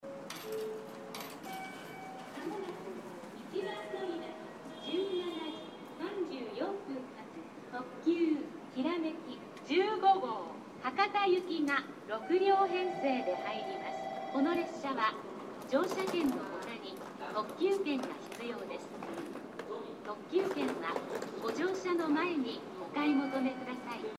スピーカーの音量はやや大きめで設置位置も低いので収録がしやすいです。
１番のりばJA：鹿児島本線
接近放送特急きらめき15号　博多行き接近放送です。